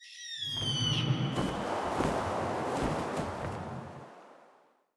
EagleFly.wav